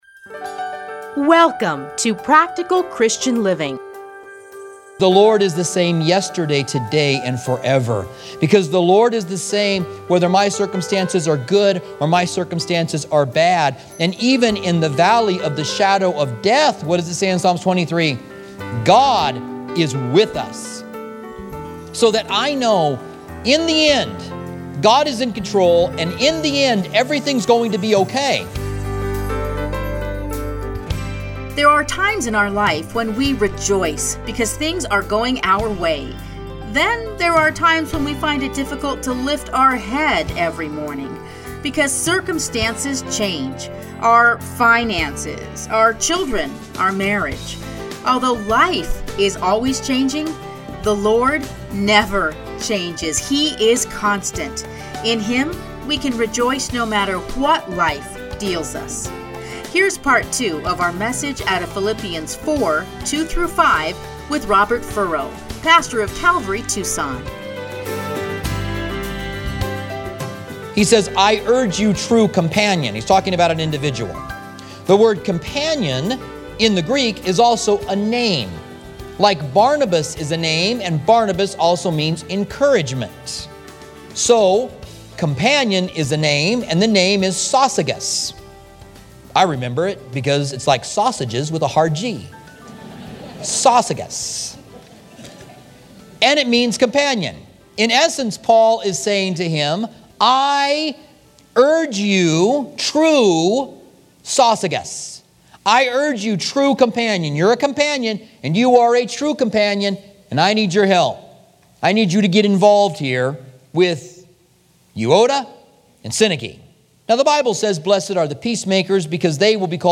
Listen here to his commentary on Philippians.